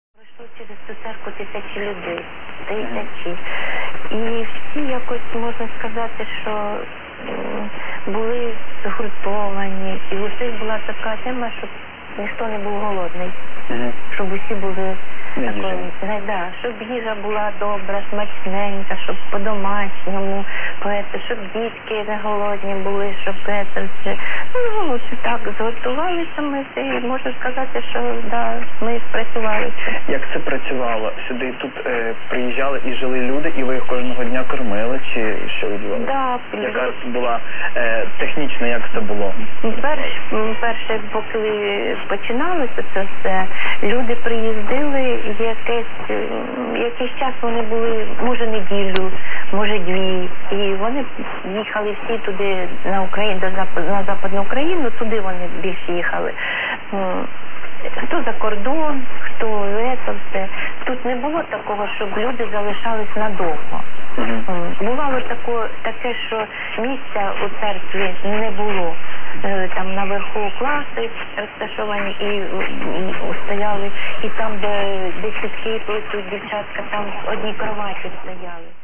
at armchair copy again with Ukrainian talk.